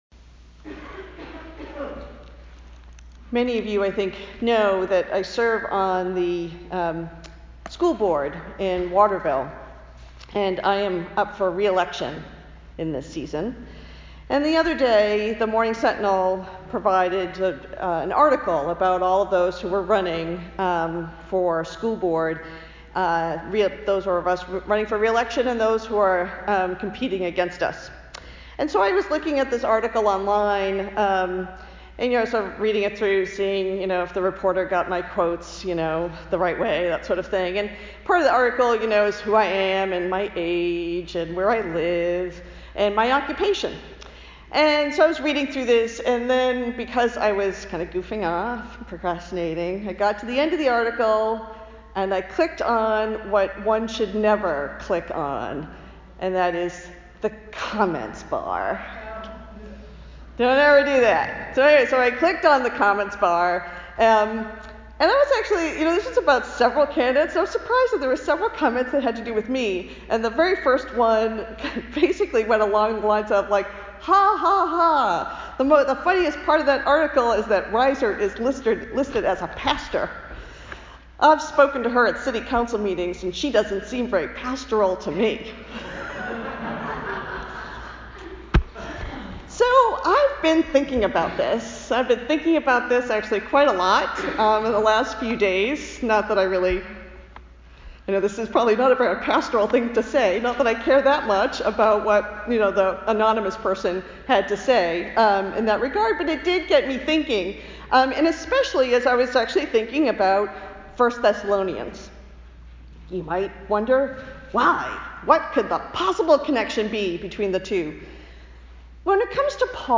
Paul as Pastor – Old South Congregational Church, United Church of Christ